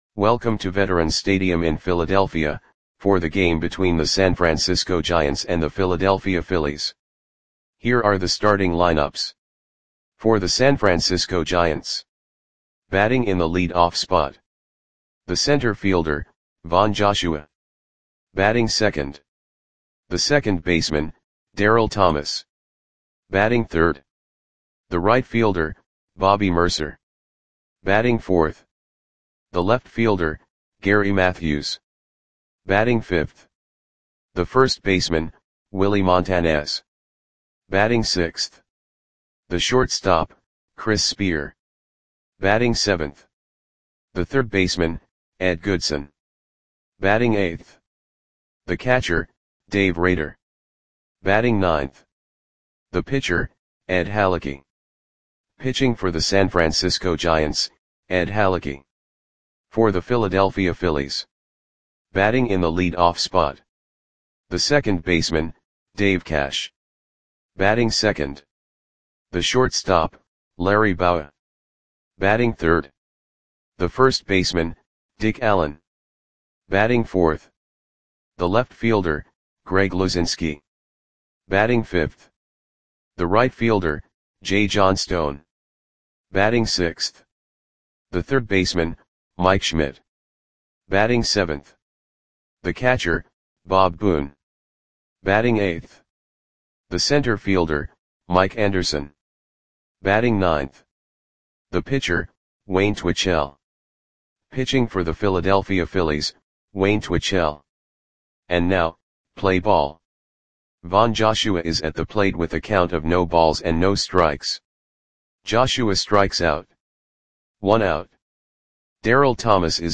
Audio Play-by-Play for Philadelphia Phillies on May 26, 1975
Click the button below to listen to the audio play-by-play.